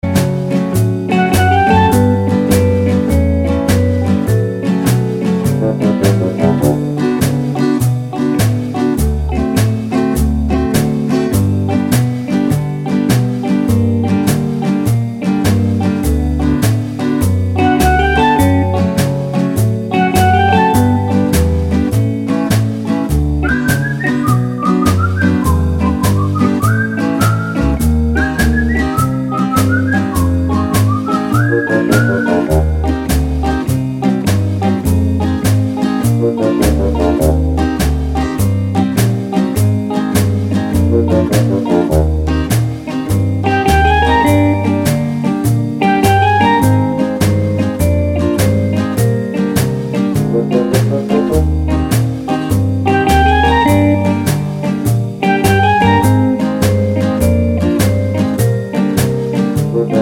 no Backing Vocals Crooners 2:43 Buy £1.50